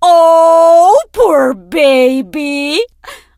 diva_kill_vo_04.ogg